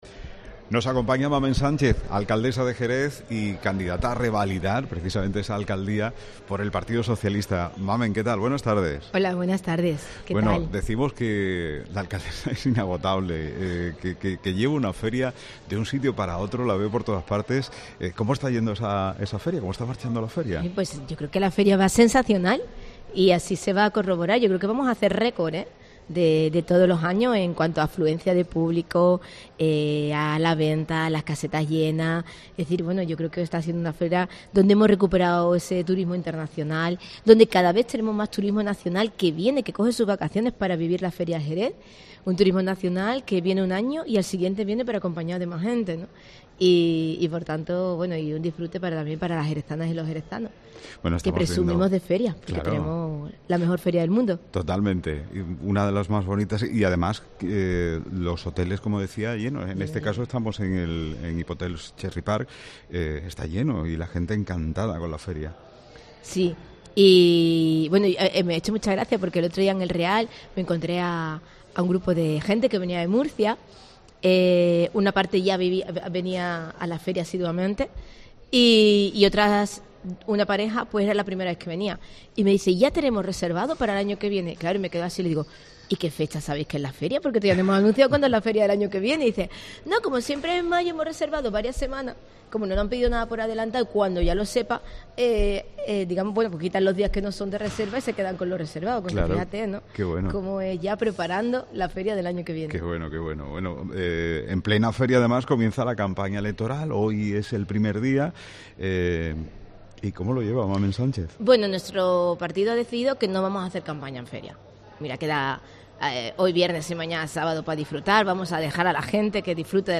Mamen Sánchez, candidata a revalidar la Alcaldía de Jerez por el PSOE Mediodía COPE - Feria del Caballo Jerez